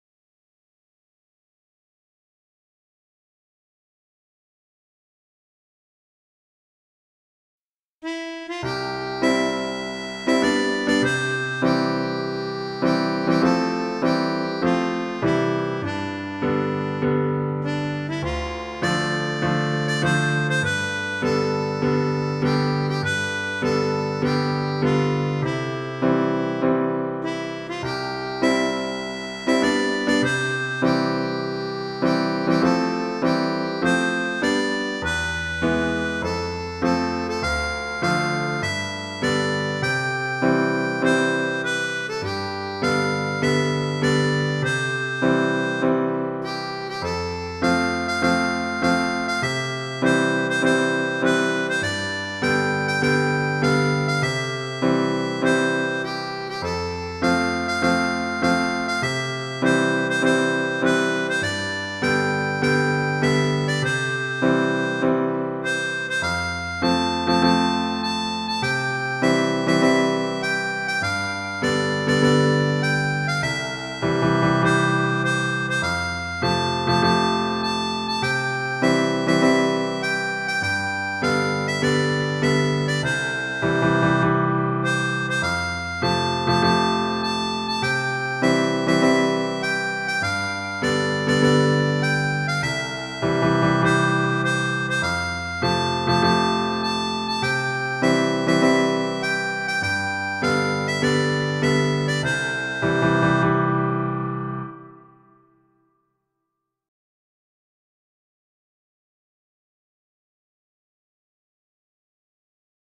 indonesia-raya-pianika
indonesia-raya-pianika.mp3